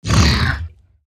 sounds / mob / zoglin / hurt1.ogg
hurt1.ogg